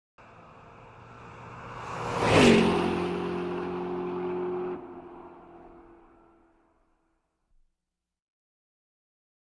SuperCar Riser SFX (Mazda furai).wav